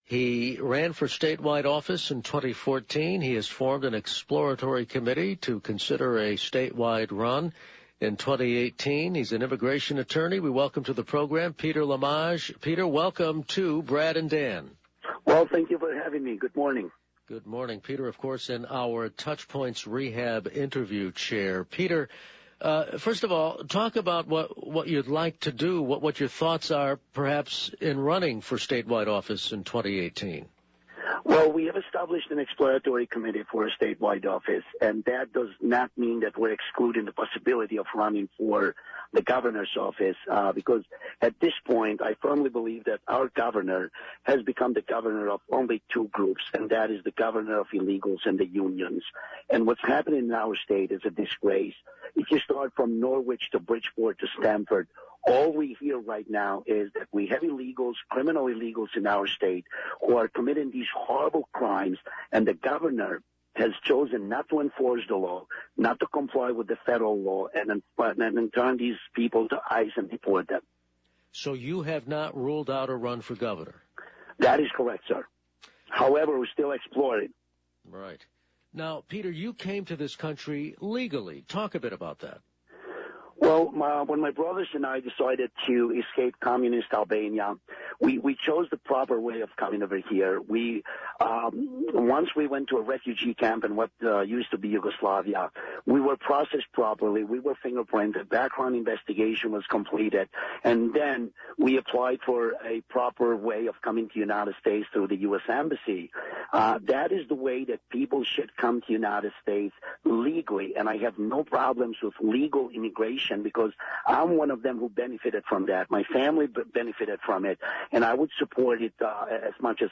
In this interview, he also hasn't rule out a run for governor.